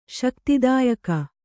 ♪ śaktidāyaka